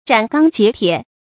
斩钢截铁 zhǎn gāng jié tiě
斩钢截铁发音